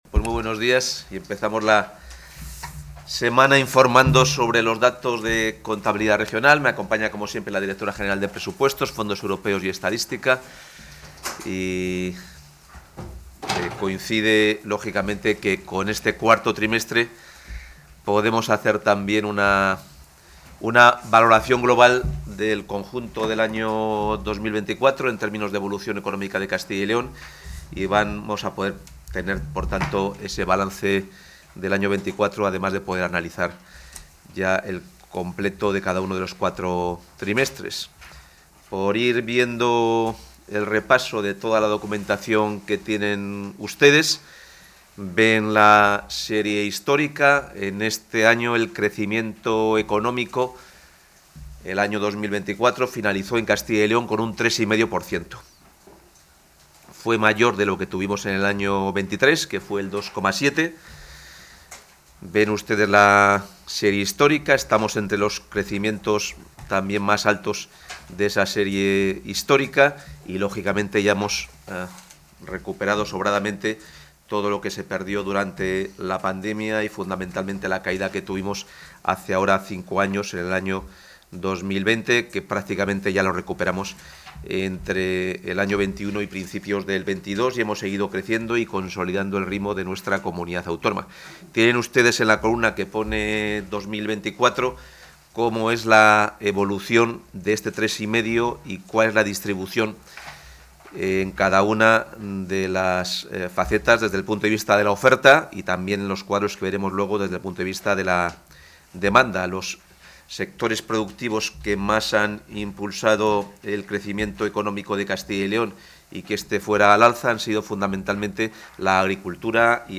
Intervención del consejero (10.823 kbytes).